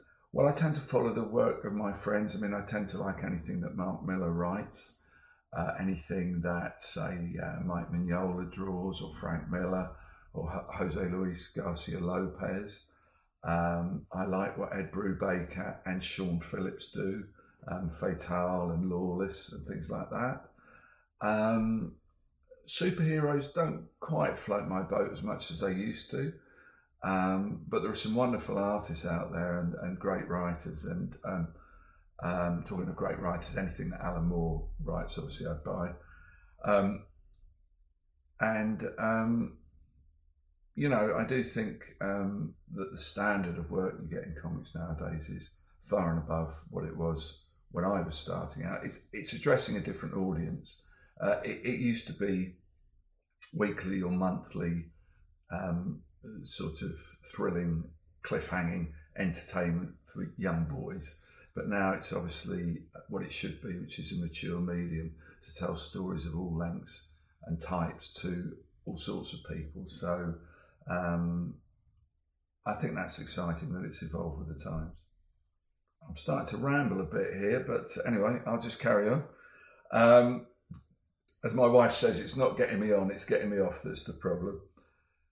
Dave Gibbons interview: Whose work do you like?